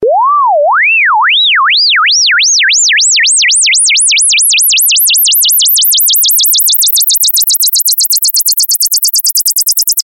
best-wake-up-alarm_24936.mp3